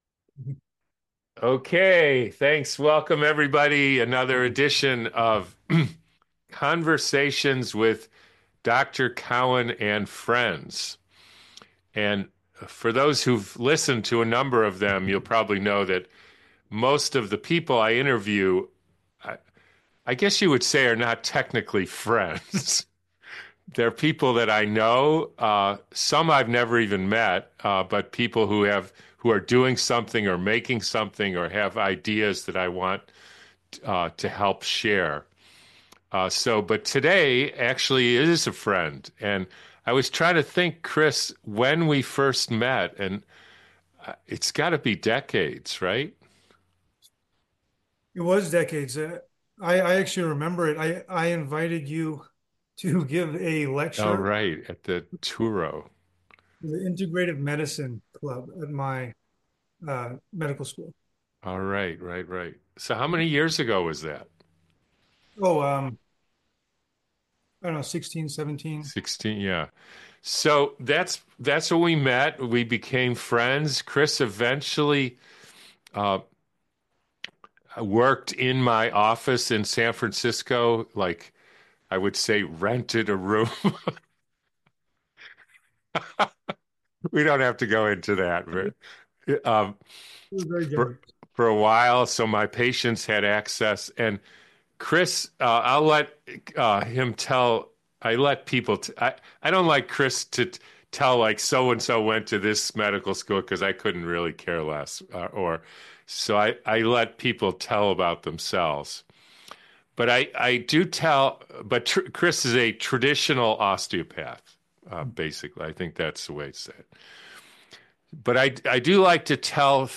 Summary ➡ In this conversation